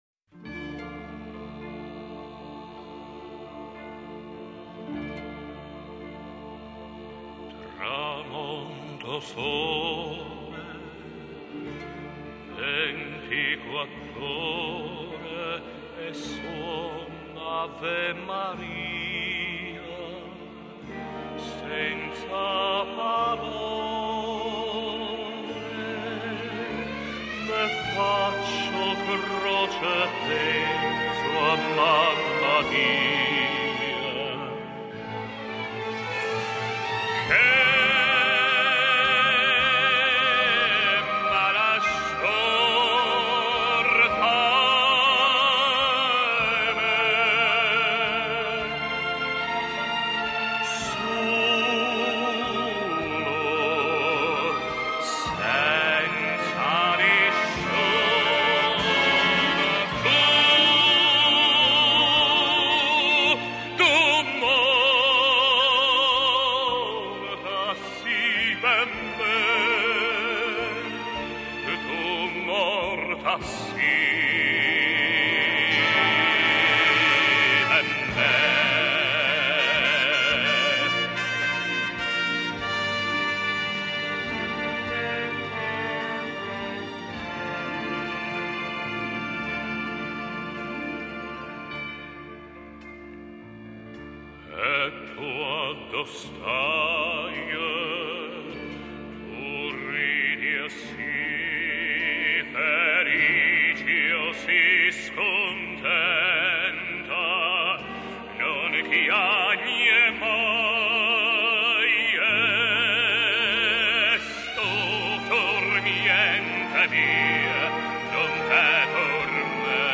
Italian songs.